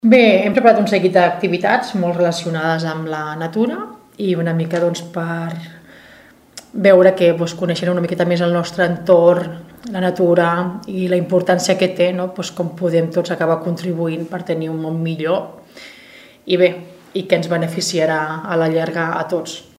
Nàdio Cantero és regidora de medi ambient.